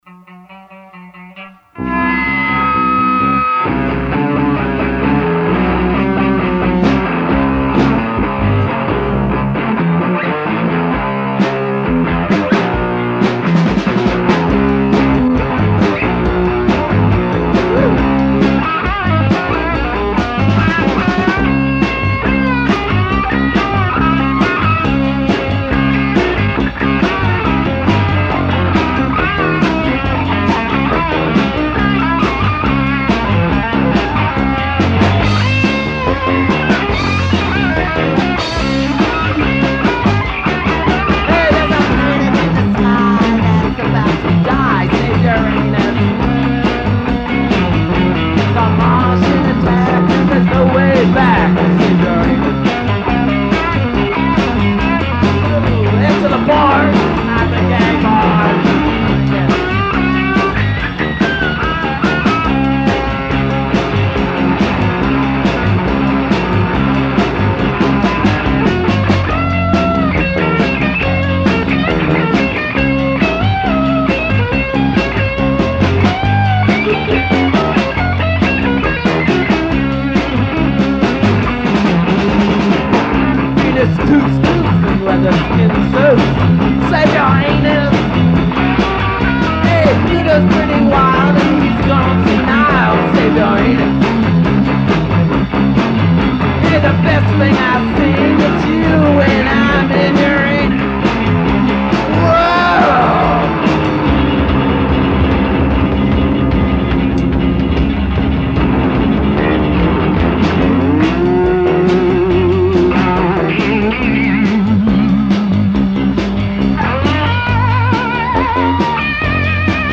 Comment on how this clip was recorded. Rehearsals 5-12 + 17-1983